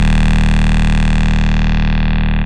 VEC1 Bass Long 30 D#.wav